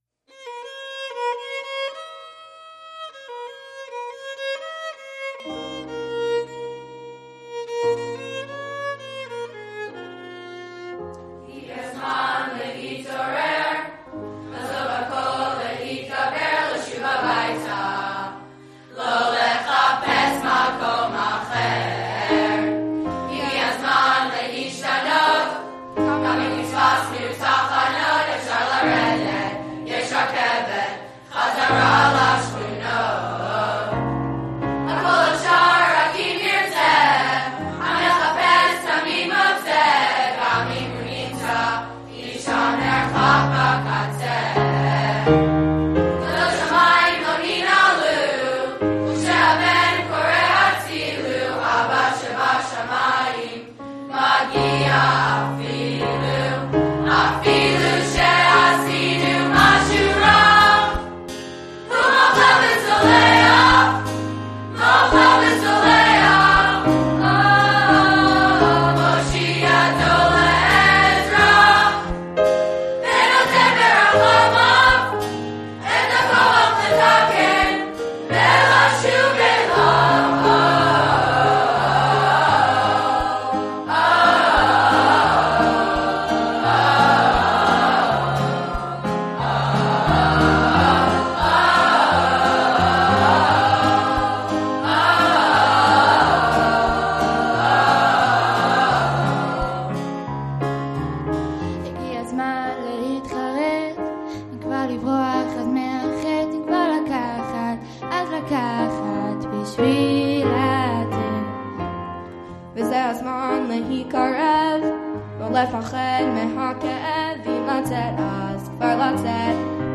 The ruach that surrounds this decades-old tradition is breathtaking, as campers, using their hands and voices, produce beautiful music.